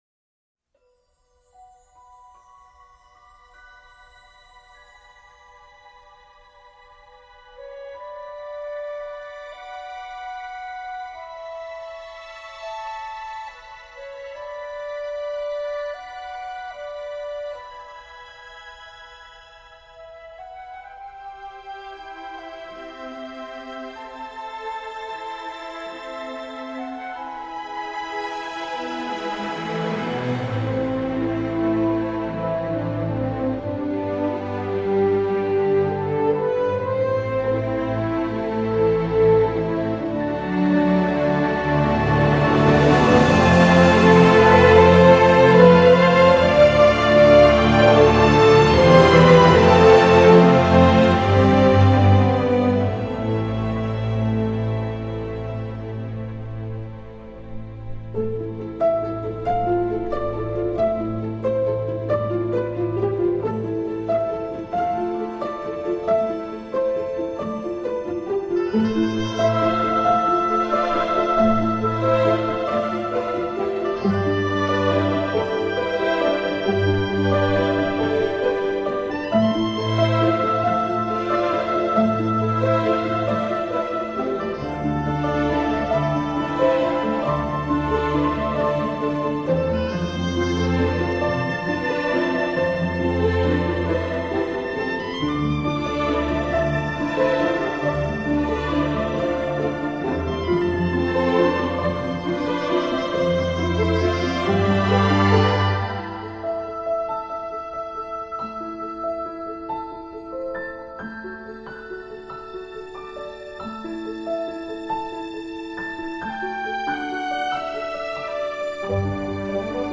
insufflant à l’ensemble un romantisme souvent poignant.
Un score à l’ancienne, dans le sens noble du terme.